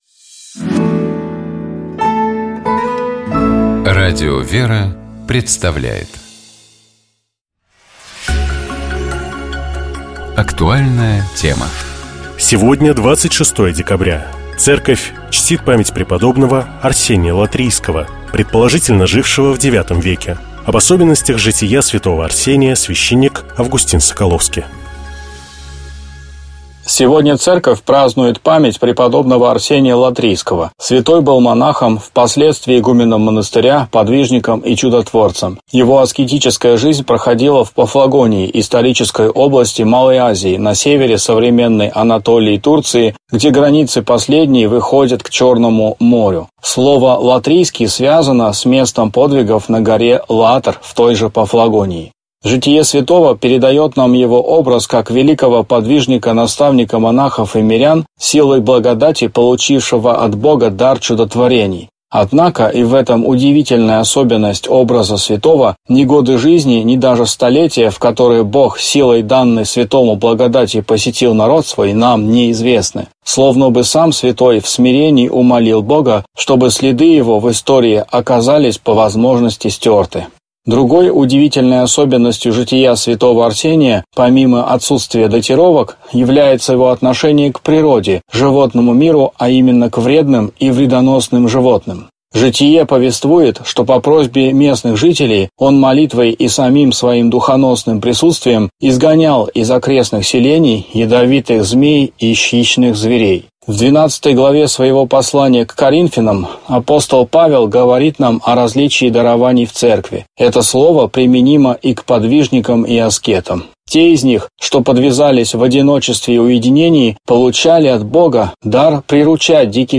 священник